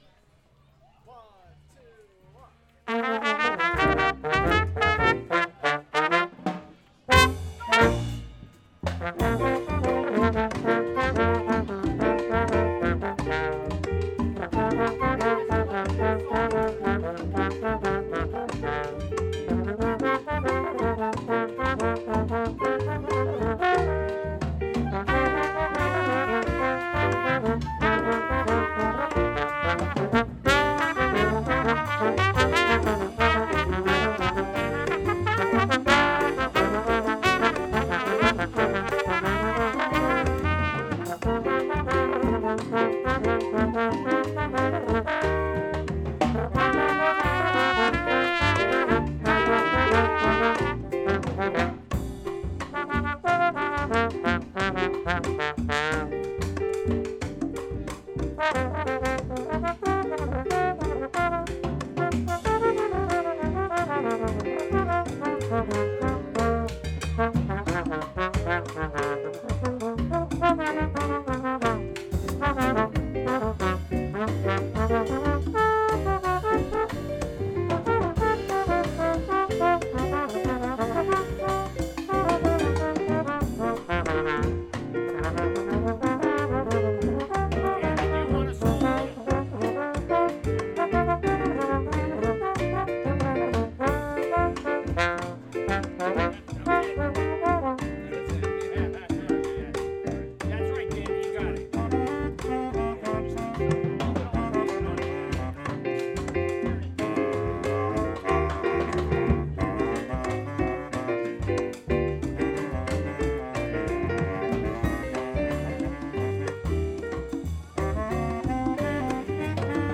Bari Sax